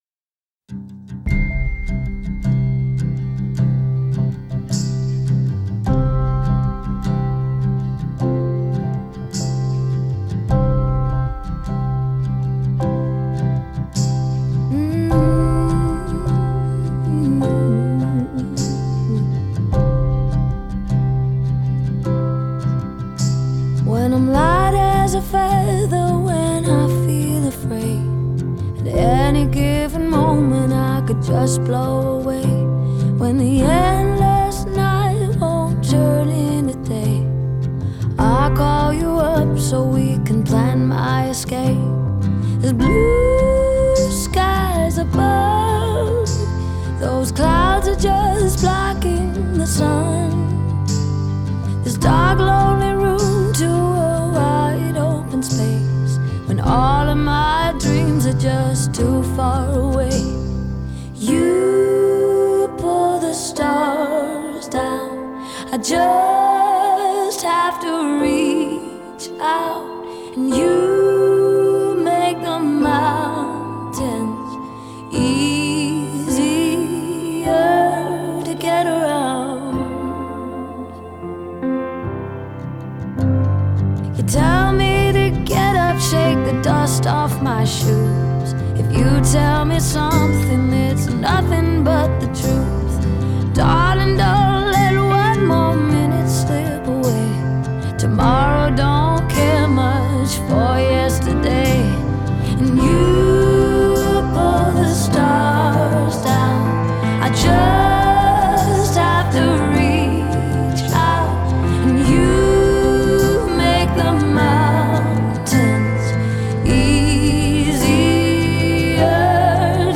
Genre: pop, female vocalists, singer-songwriter